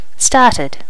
Additional sounds, some clean up but still need to do click removal on the majority.